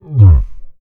MONSTER_Ugh_05_mono.wav